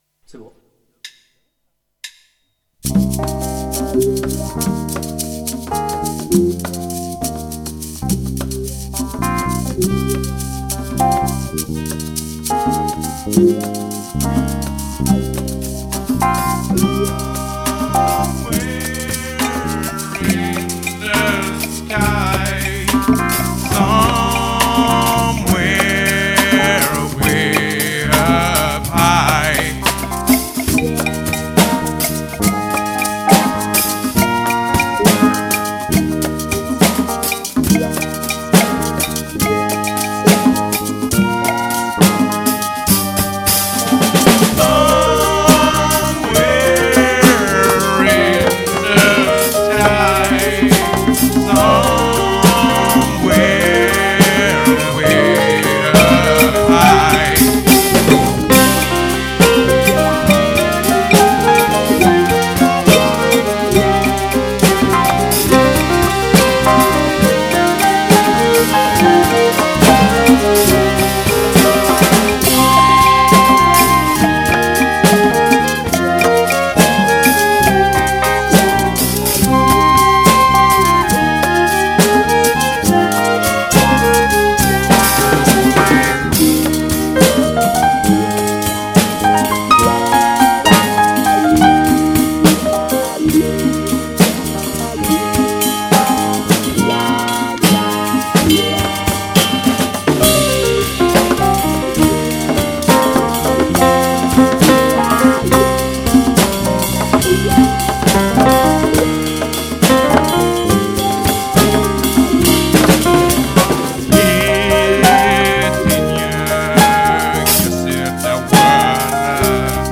en 6/8
Structure AABA'
A : Chorus Piano + Melodica
A : Chorus Guitare + Chant
B : Thème Flutes B + violons